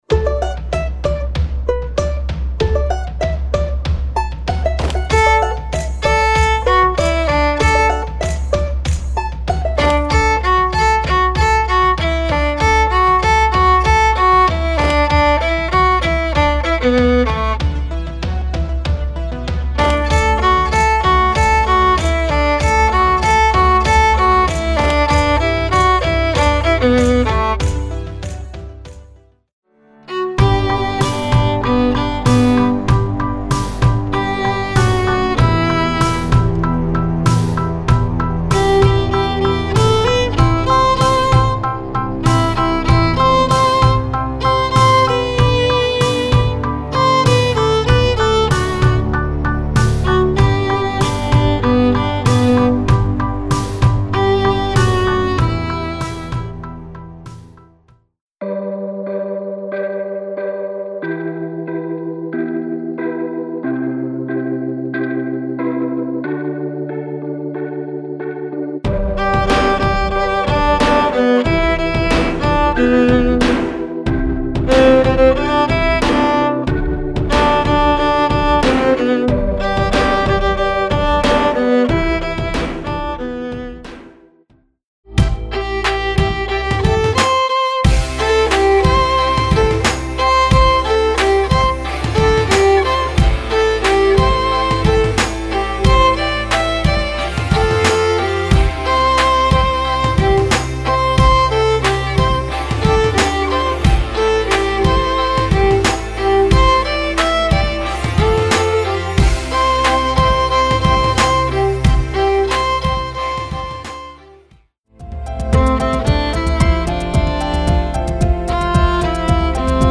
Voicing: Vla w/ Audio